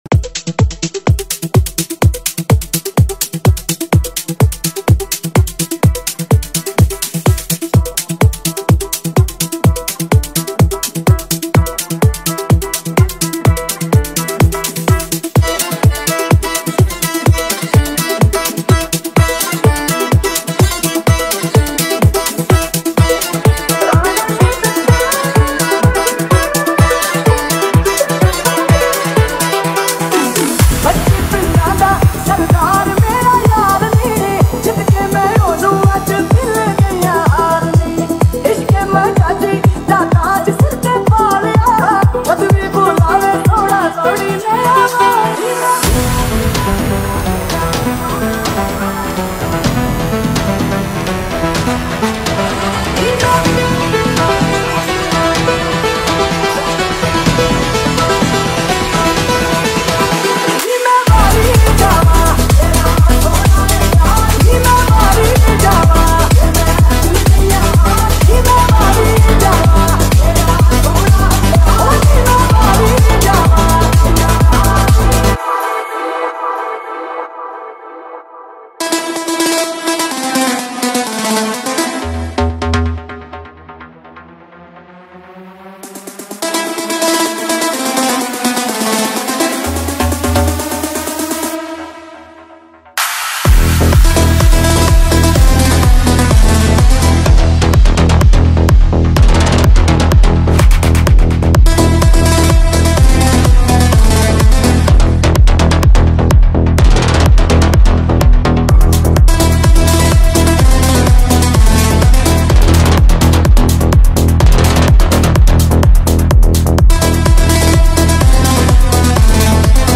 • Mix Type: Techno Mix / High Bass
• Category: Bollywood DJ Remix
• 🔊 Deep Bass Techno Sound